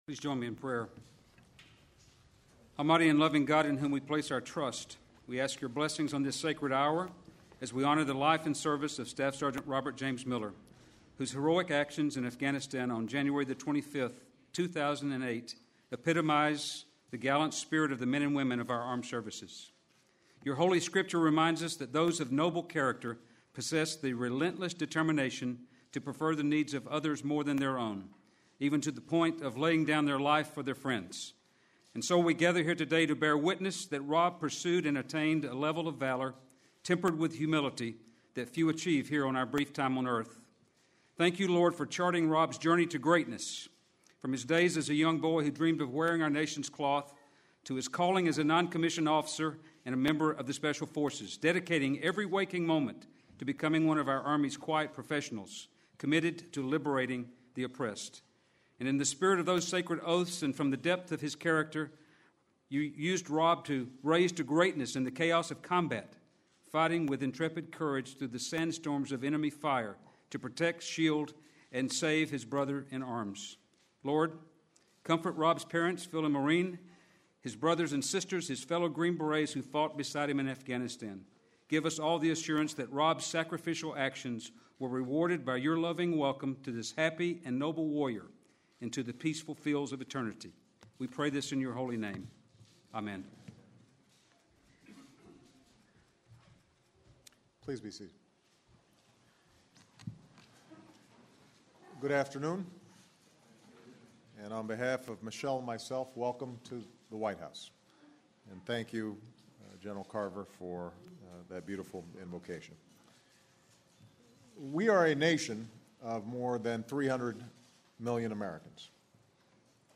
U.S. Army Major General Chaplain Douglas L. Carver delivers the invocation and the benediction.
Recorded in White House East Room, Oct. 6, 2010.